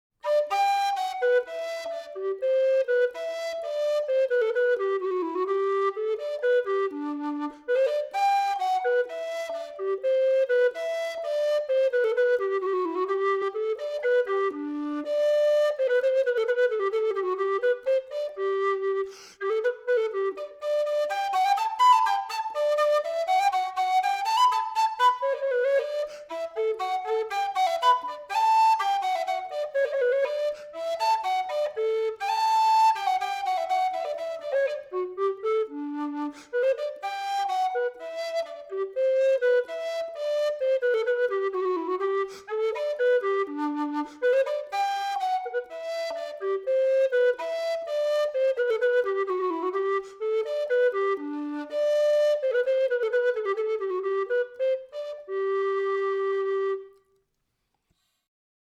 Wooden Low Whistle in D
The sound of the rosewood low whistle takes a position between blackwood and mopane.
The sound samples underneath the pictures demonstrate the wonderful but slightly different sound of these instruments.